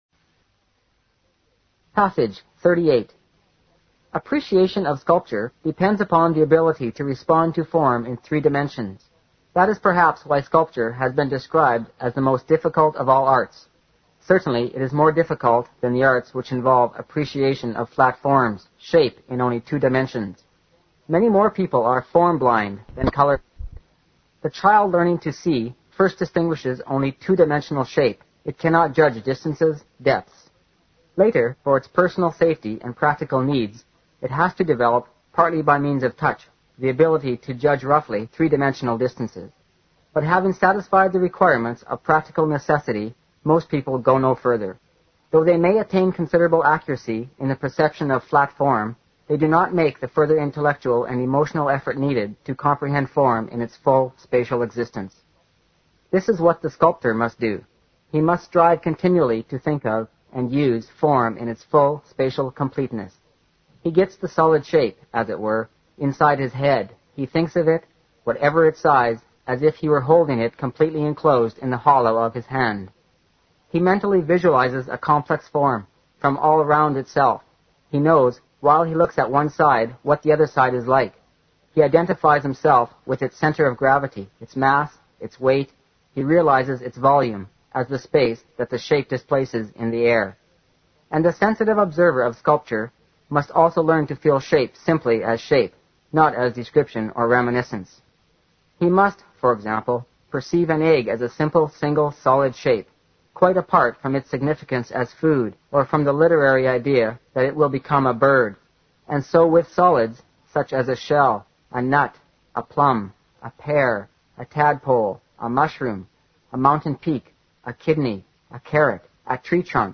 新概念英语85年上外美音版第四册 第38课 听力文件下载—在线英语听力室